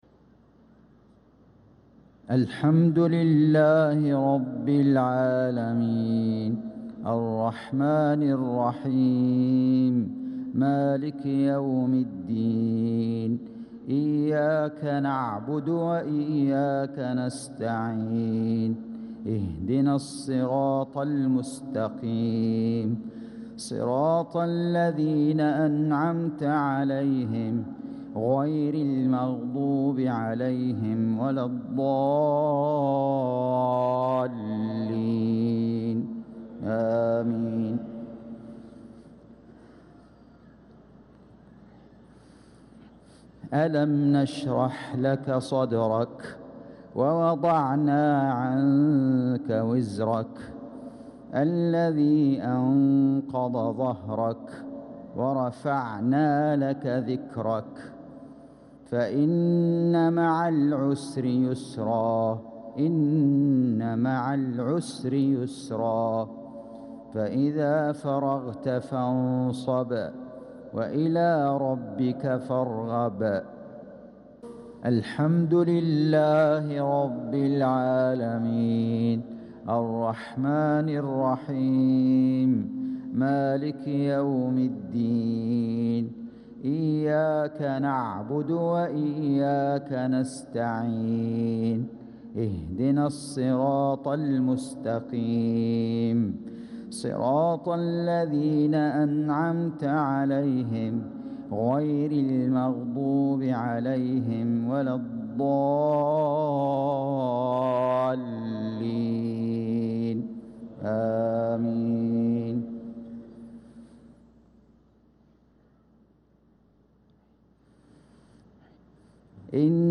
صلاة المغرب للقارئ فيصل غزاوي 19 ذو الحجة 1445 هـ